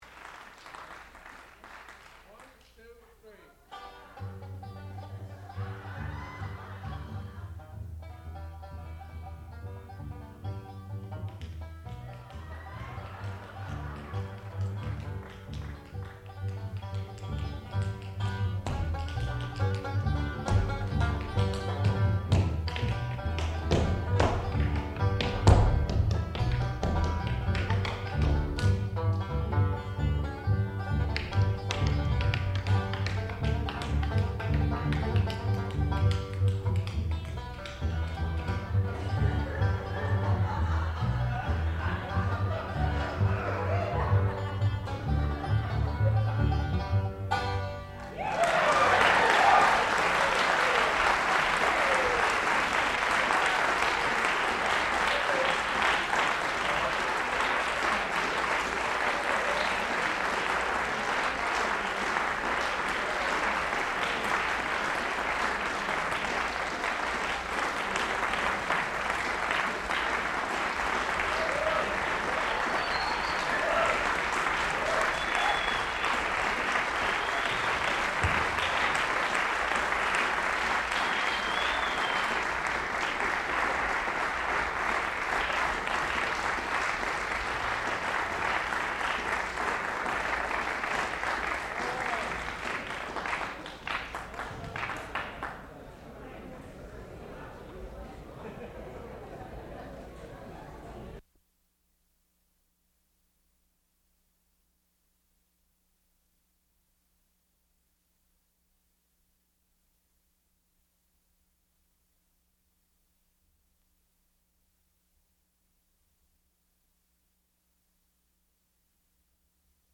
sound recording-musical
classical music
tuba
double bass